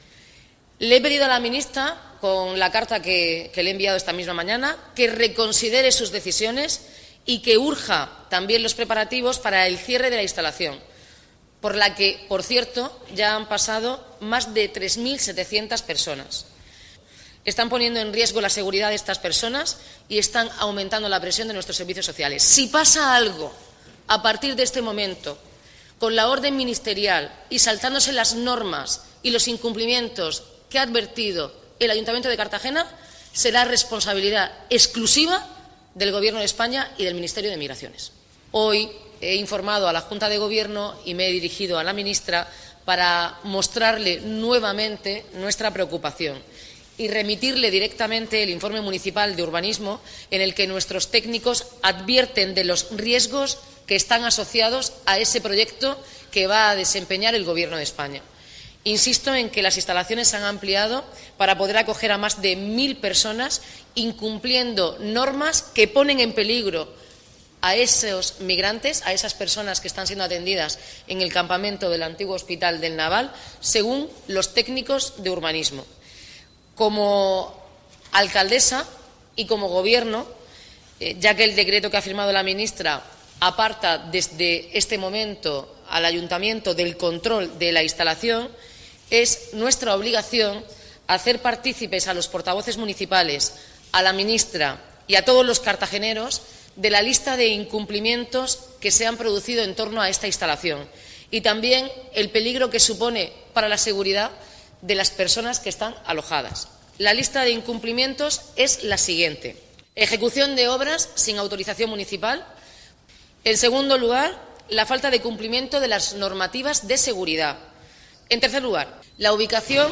Audio: Declaraciones de la alcaldesa, Noelia Arroyo, sobre orden del Ministerio de Migraciones (MP3 - 9,71 MB)